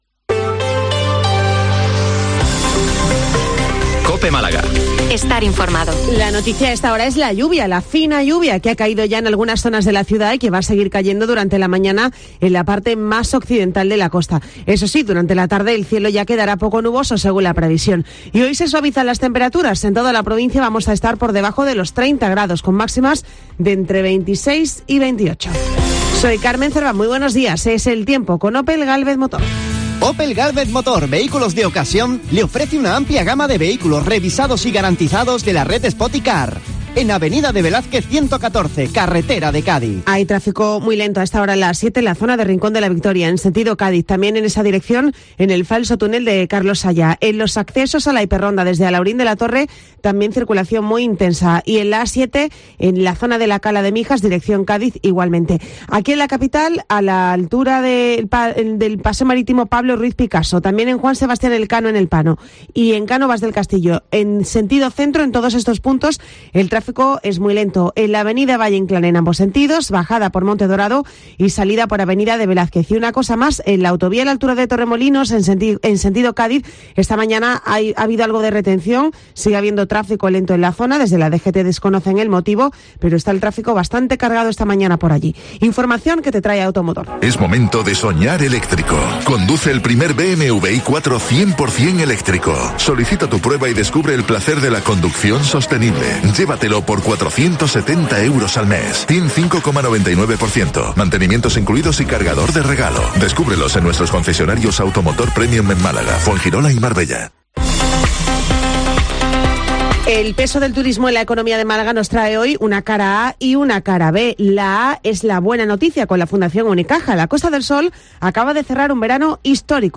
Informativo 08:24 Málaga - 041023